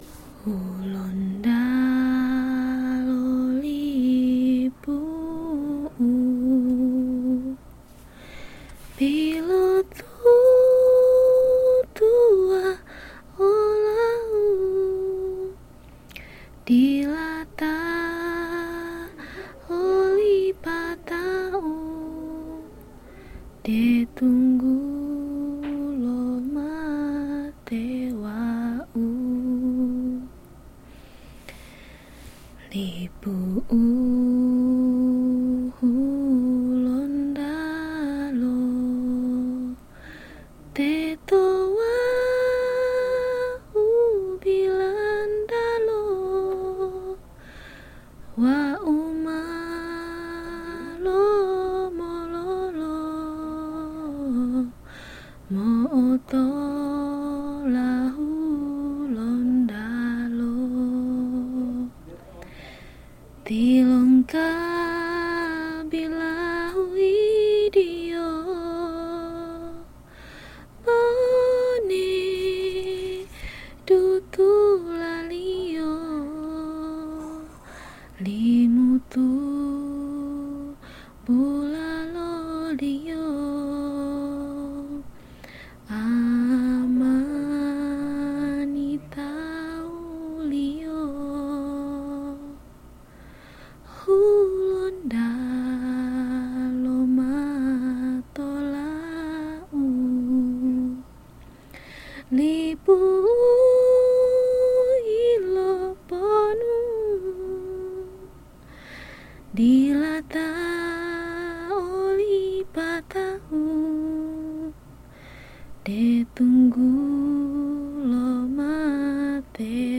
This is a famous song in Gorontalo. It tells about someone who travels abroad and misses his hometown.